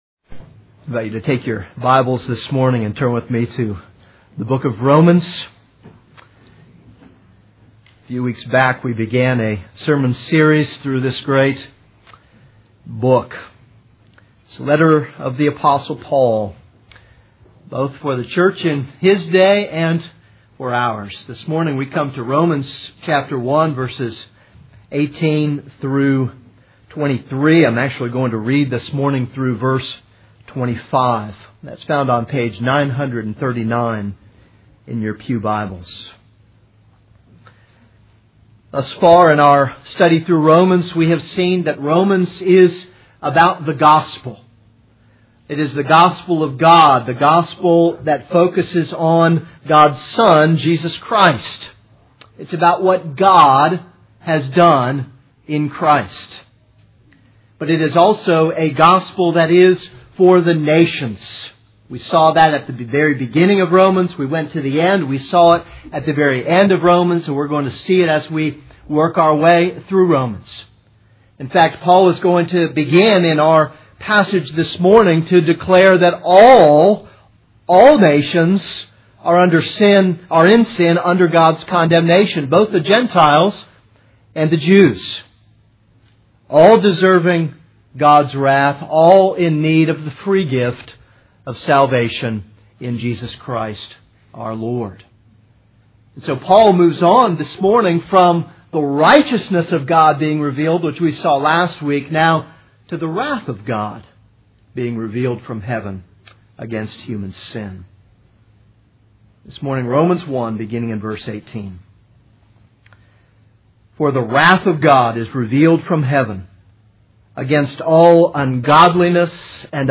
This is a sermon on Romans 1:18-23.